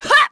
Seria-Vox_Attack1_kr.wav